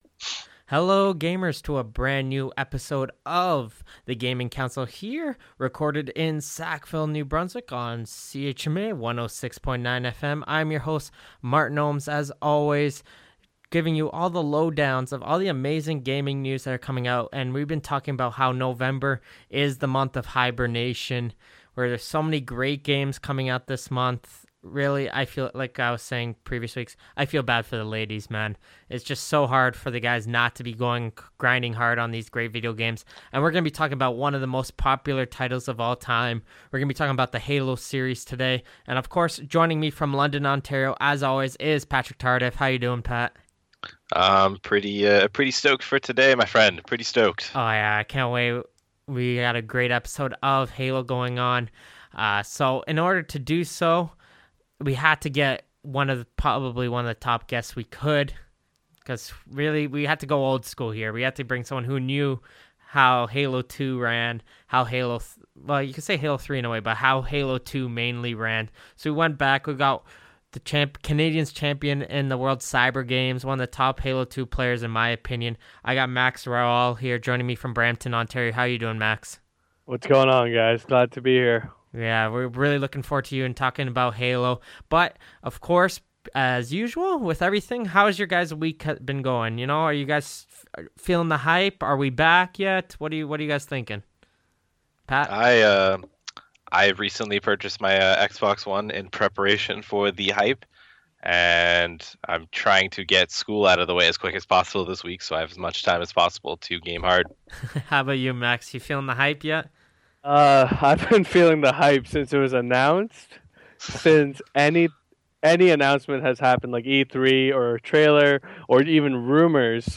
A Weekly Discussion of Gaming and Technology